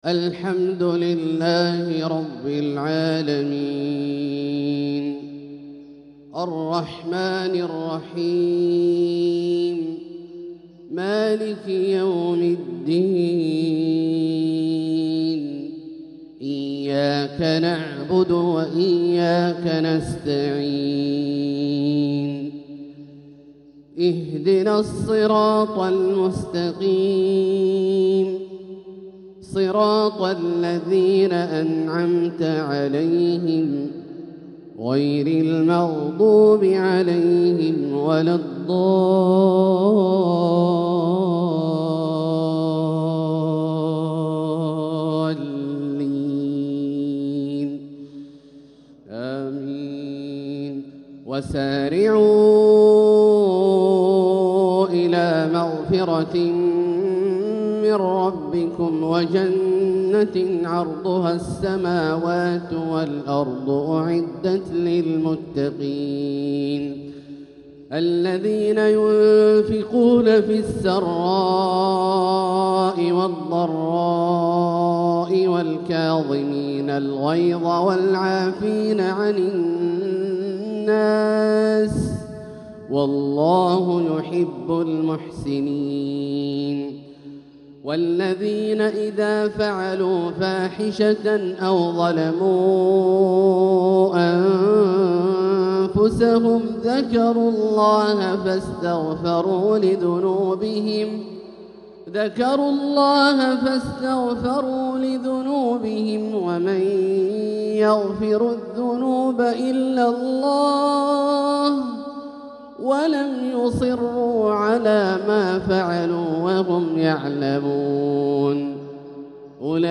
تلاوة طيبة من سورة آل عمران | فجر الخميس 9-7-1446هـ > ١٤٤٦ هـ > الفروض - تلاوات عبدالله الجهني